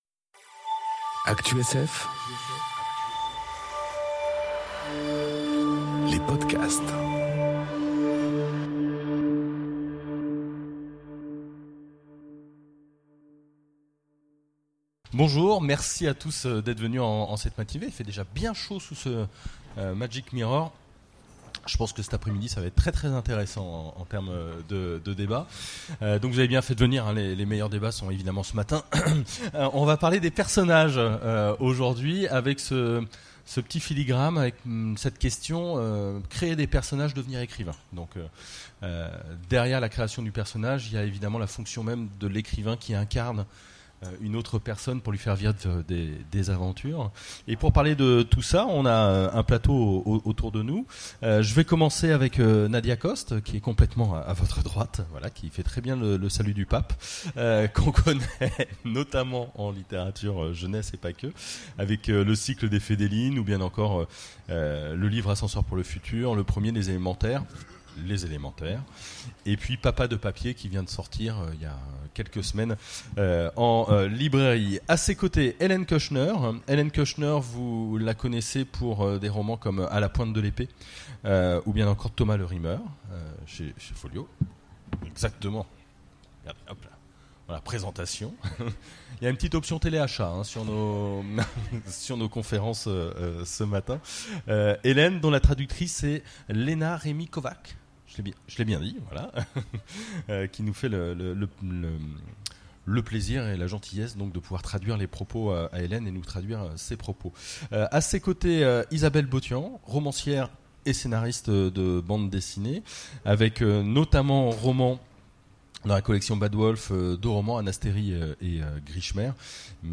Conférence Créer des personnages... Devenir écrivain enregistrée aux Imaginales 2018